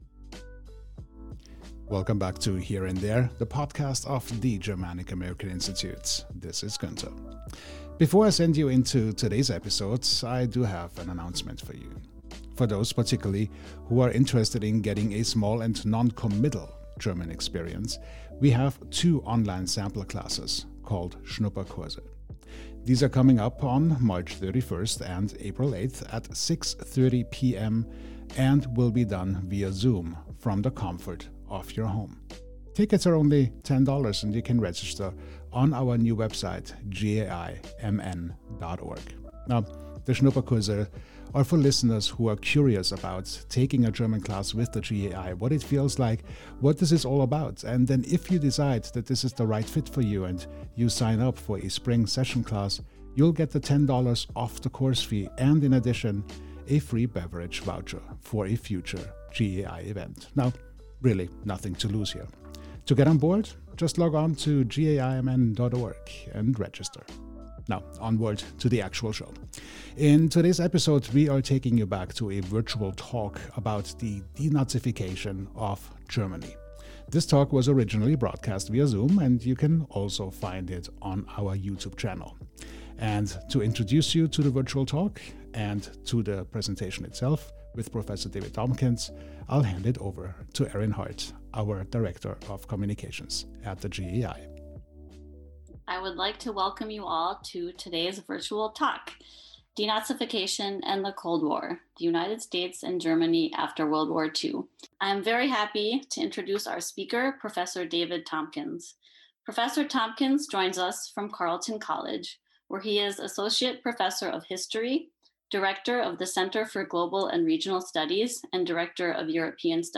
S3E9 - Virtual Talk: Denazification and the Cold War—The United States and Germany after WWII - Germanic-American Institute